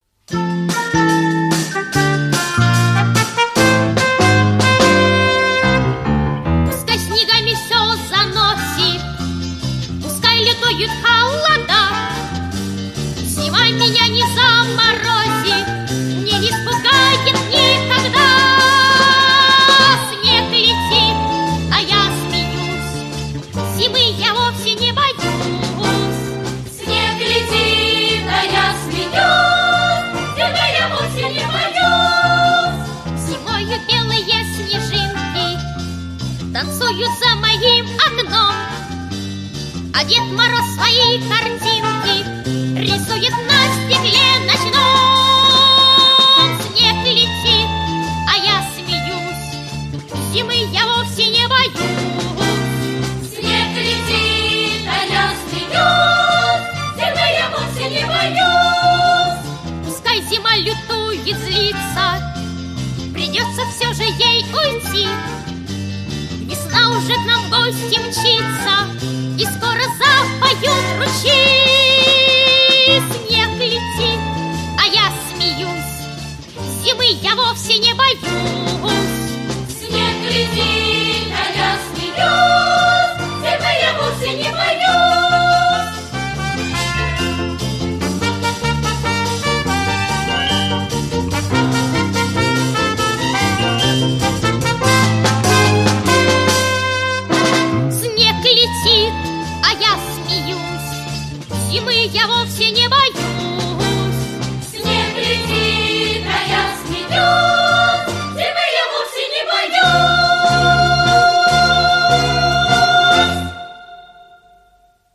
Песенки про зиму Текст песни